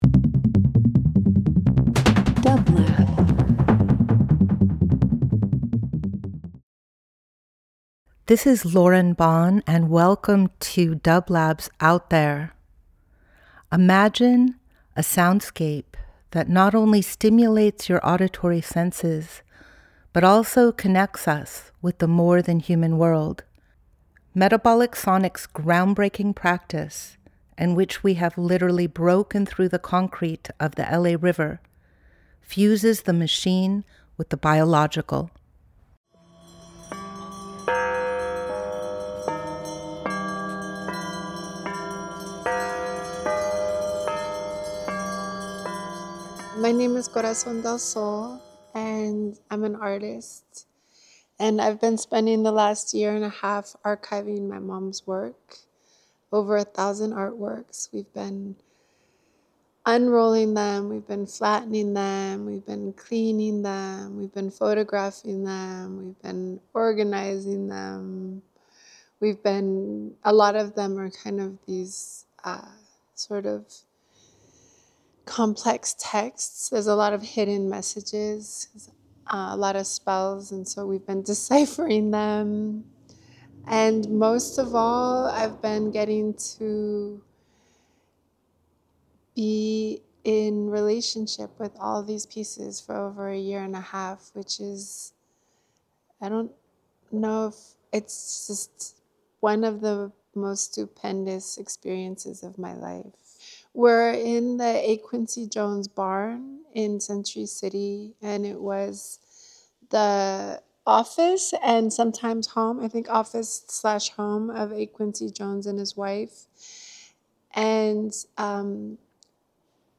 Each week we present field recordings that will transport you through the power of sound.
Metabolic Sonics Metabolic Studio Out There ~ a field recording program 03.05.26 Field Recording Interview Talk Show Voyage with dublab into new worlds.